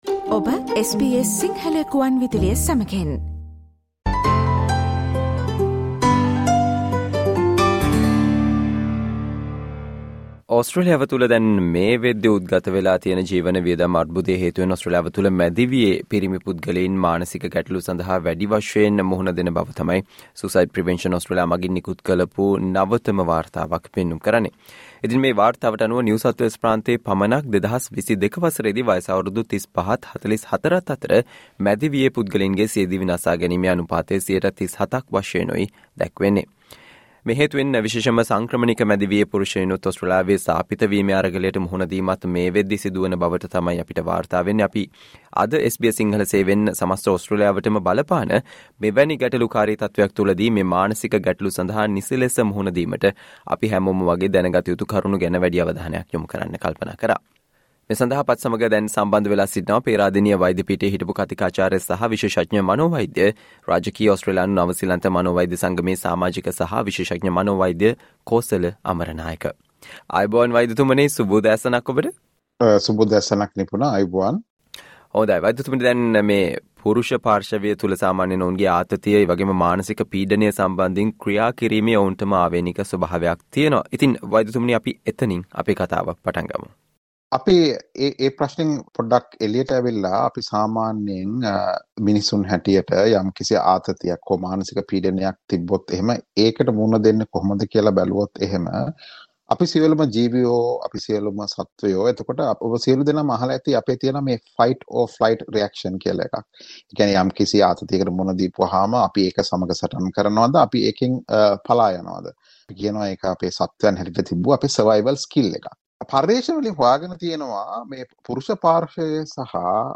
SBS Sinhala discussion on Important factors for men in Australia to reduce stress caused by high cost of living